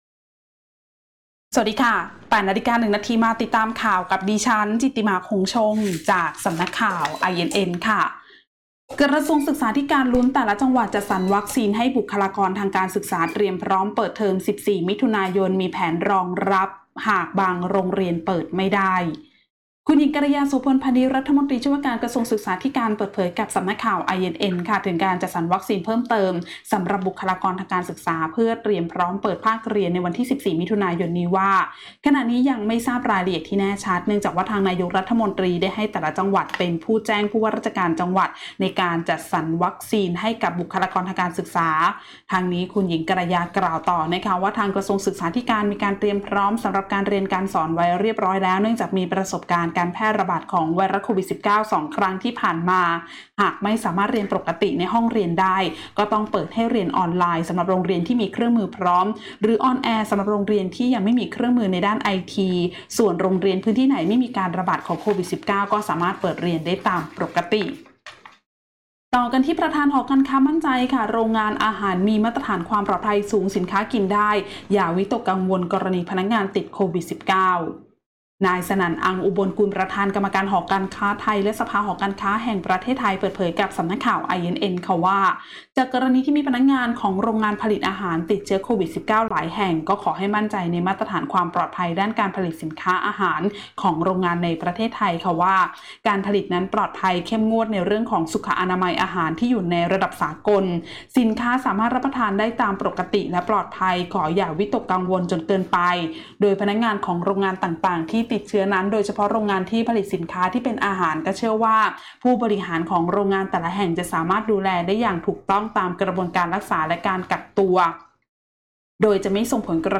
ข่าวต้นชั่วโมง 08.00 น.